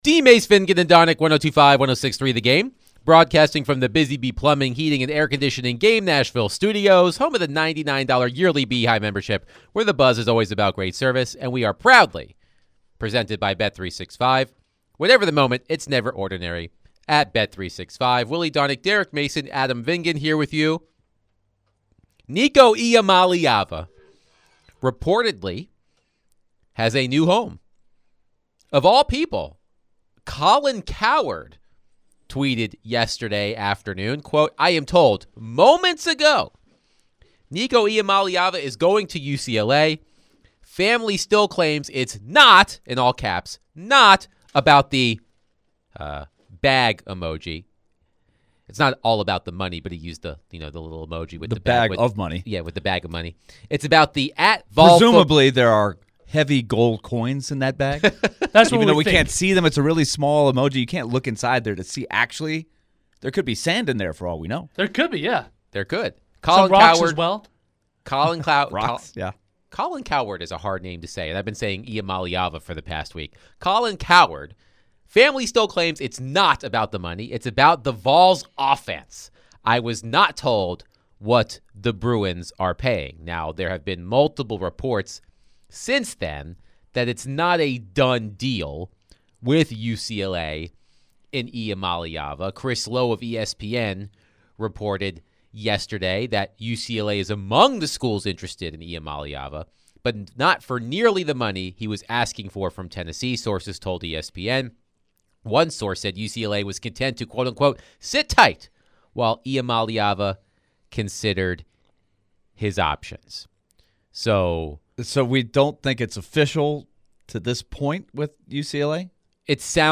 In the second hour, DVD discuss the news of Nico Iamaleava may have already found a new home at UCLA. They gave some reaction, go in-depth on the transfer portal for College football and more phones as well.